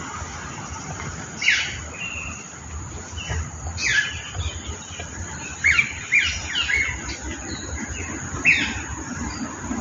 Nombre científico: Sphecotheres vieilloti
Nombre en inglés: Australasian Figbird
Localidad o área protegida: Lamington National Park
Condición: Silvestre
Certeza: Vocalización Grabada
australasian-figbird.mp3